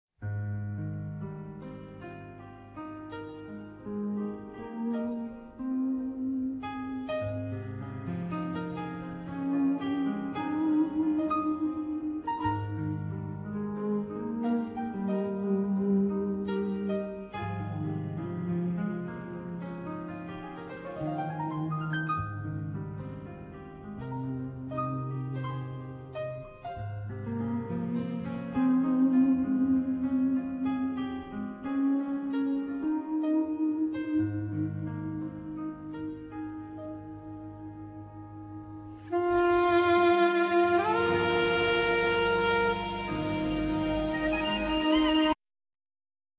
Alto sax,synthesizer
Piano,Keyboards
Guitar,Sitar
Bass
Percussion
Tenor sax
Trumpet
Drums